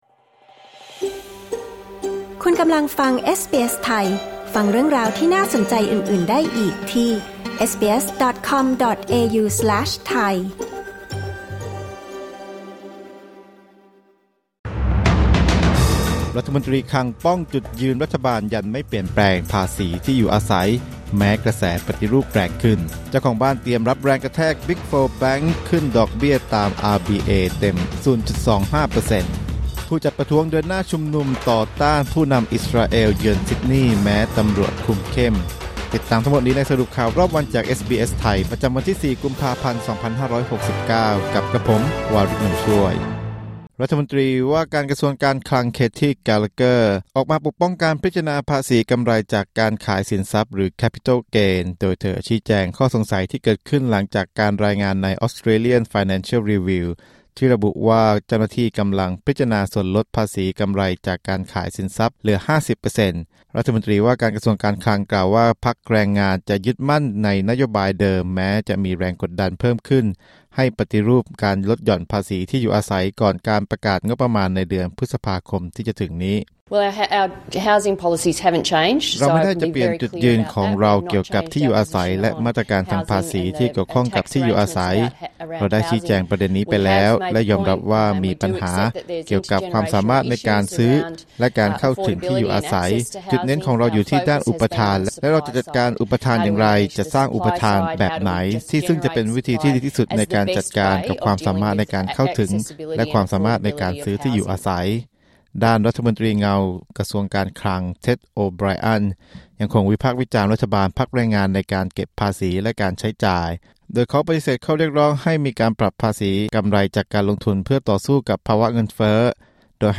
สรุปข่าวรอบวัน 4 กุมภาพันธ์ 2569